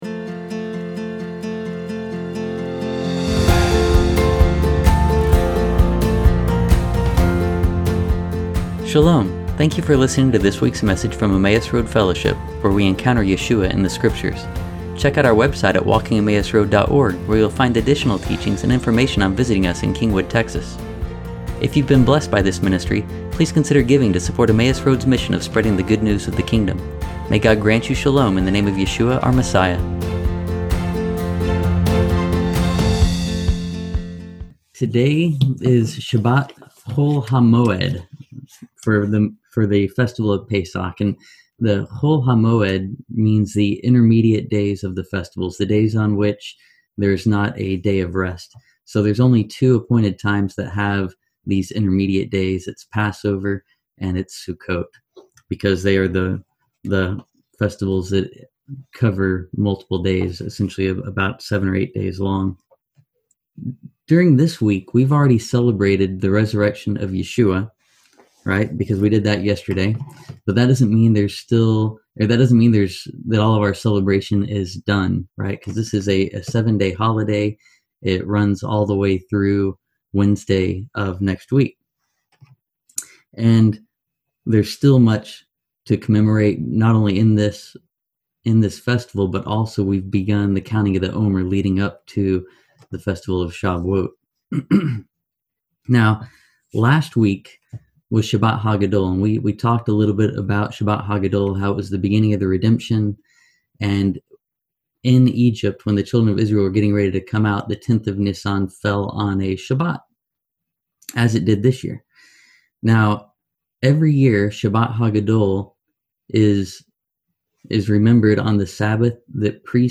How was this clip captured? We are a Messianic congregation that follows Yeshua, the Messiah.